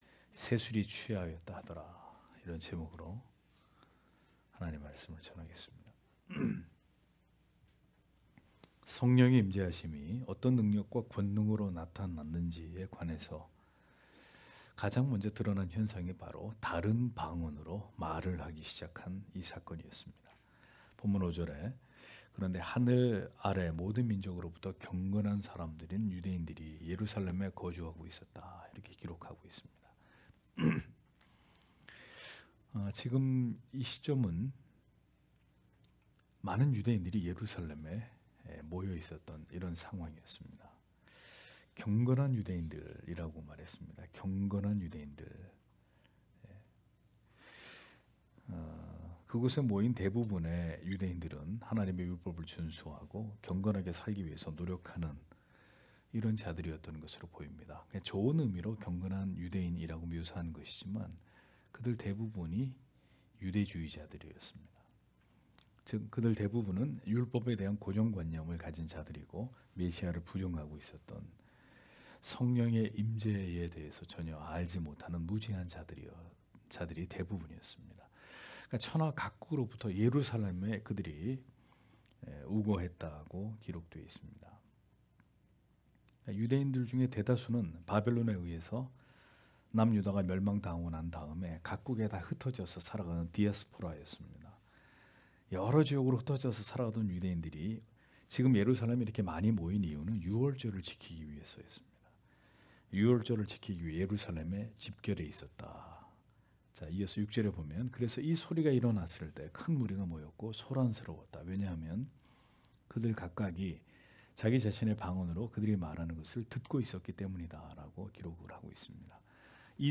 금요기도회 - [사도행전 강해 10] 새 술이 취하였다 하더라 (행 2장 5- 13절)